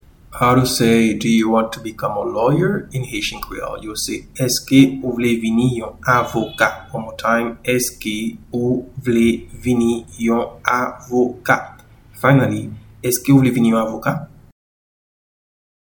Pronunciation and Transcript:
Do-you-want-to-become-a-lawyer-in-Haitian-Creole-Eske-ou-vle-vini-yon-avoka.mp3